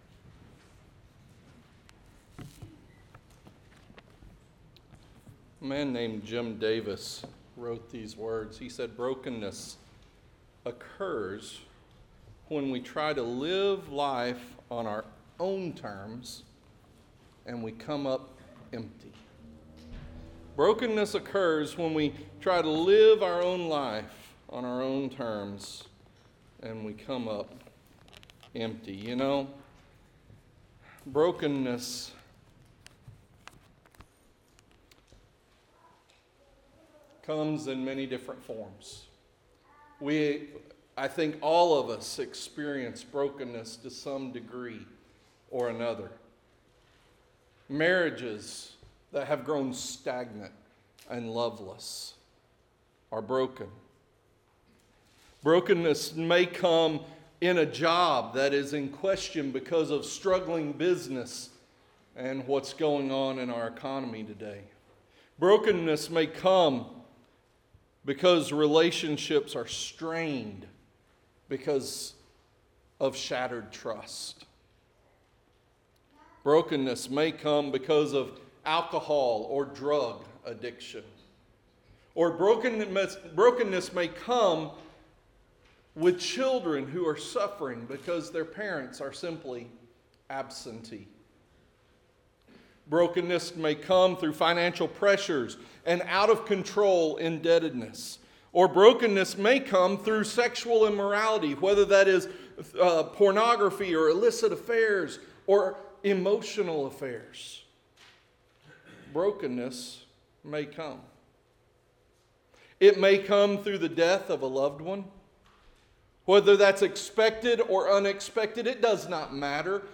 Interactive Sermon Notes Recharge – Full PDF Download Recharge – Week 4 Download Series: Nehemiah: Rebuild & Renew , REBUILD: Restoring the Broken